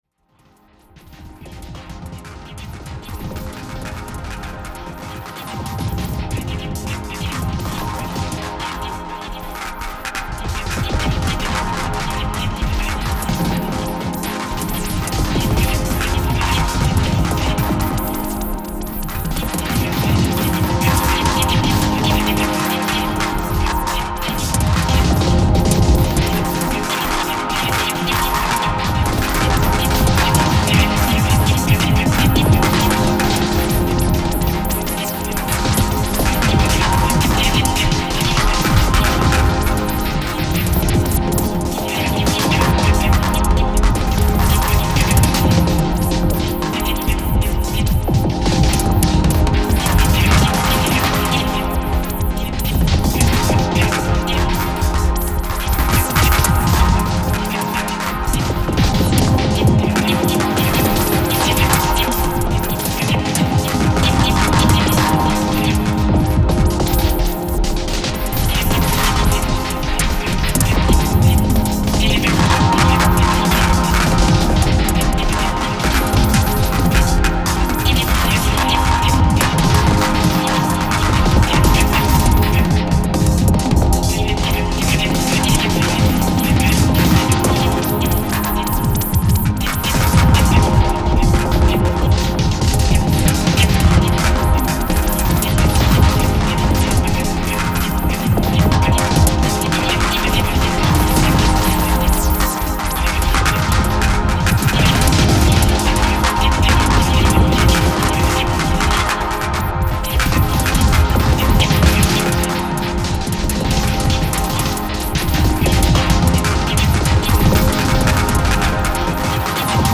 本作は久々のコンピュータのみによるオーケストレーション作品。
私にはアーバンで近未来的に聴こえる一方で、人類が誕生する以前の超太古の原始の海に降り注ぐ雨音のようにも聴こえます。